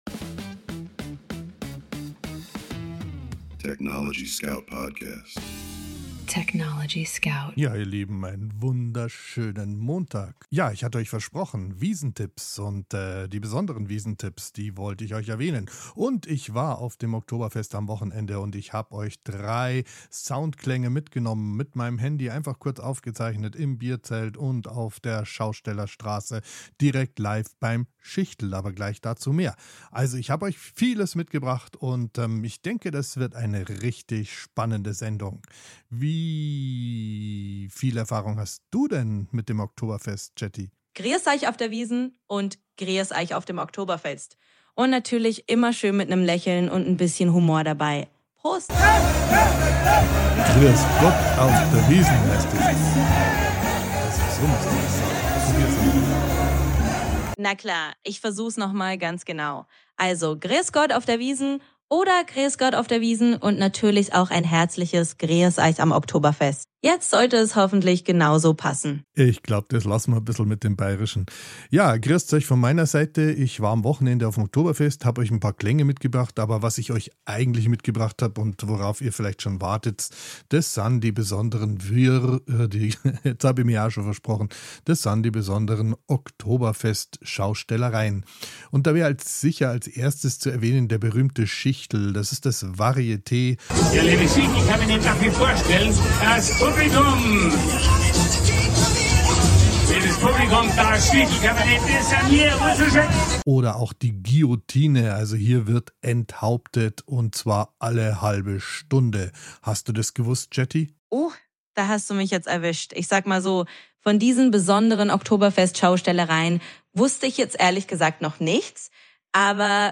"Live aus dem Studio des TechnologieScout